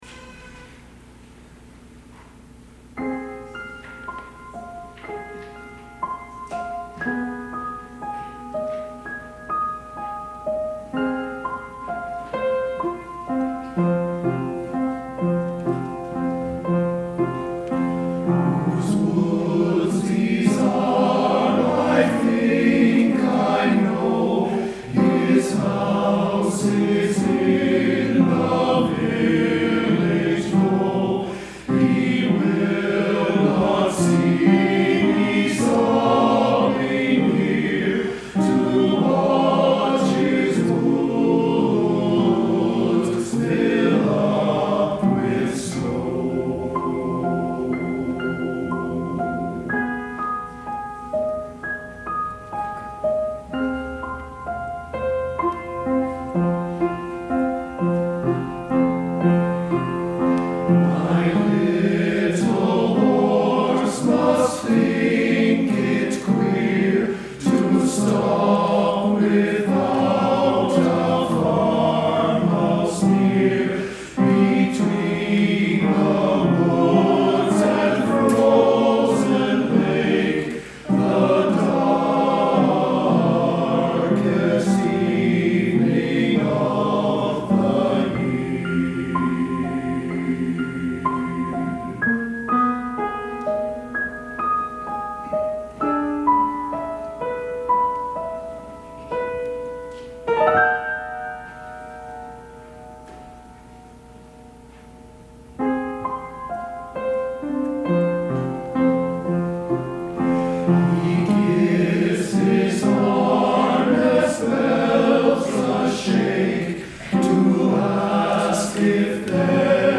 Below you will find select audio and video from our past performances for your listening and viewing enjoyment.
From our April 7, 2018 concert, Sharing Our Choral Art VI:
Performed by the Men of NNV.